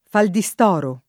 vai all'elenco alfabetico delle voci ingrandisci il carattere 100% rimpicciolisci il carattere stampa invia tramite posta elettronica codividi su Facebook faldistorio [ faldi S t 0 r L o ] o faldistoro [ faldi S t 0 ro ] s. m.; pl.